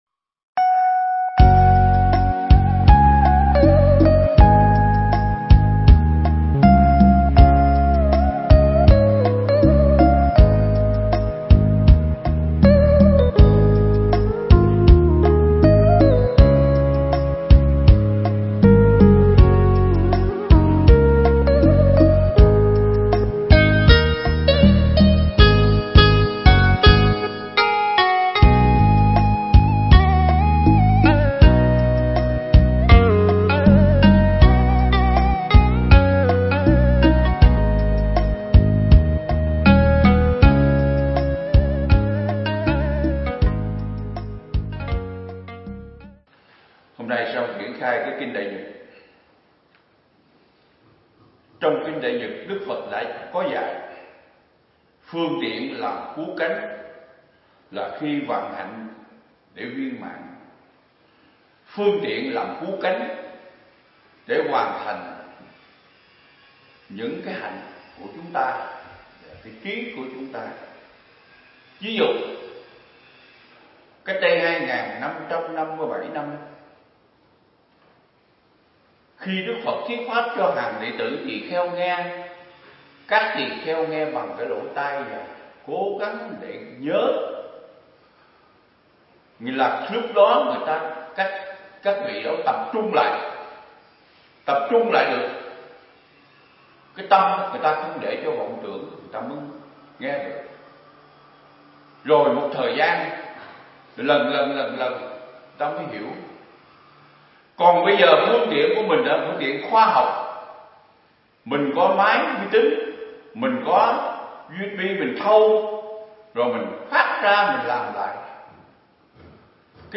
Thuyết Pháp
giảng tại Viện Nghiên Cứu Và Ứng Dụng Buddha Yoga Việt Nam (TP Đà Lạt)